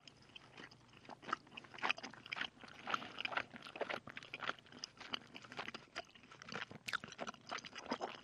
Chewing A Piece Of Gum with Lip Smacks & Swallows